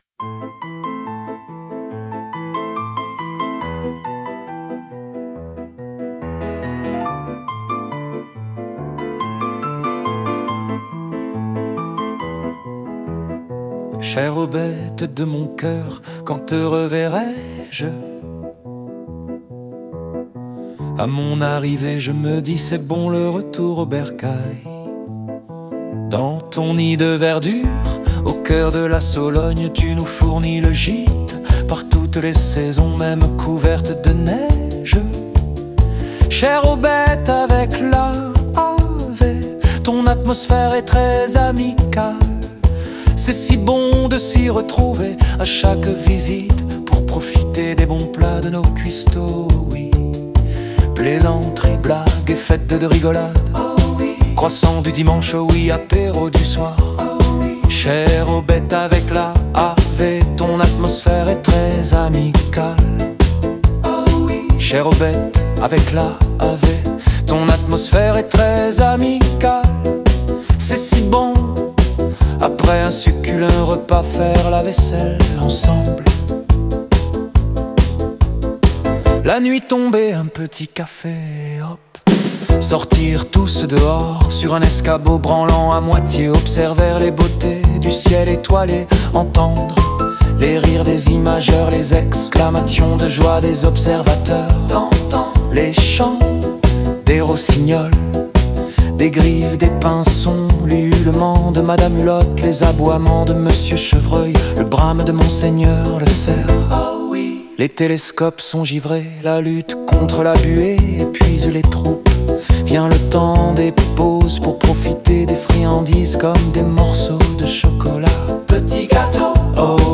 Rock, Passionné, Joyeux, Énergique, Féminin, Masculin, Chœur, Duo, Voix rauque, Profond, Violon, Guitare, Saxophone, Batterie, Piano, 140BPM
et une autre avec dominante piano
002-aubette-de-mon-coeur-piano.mp3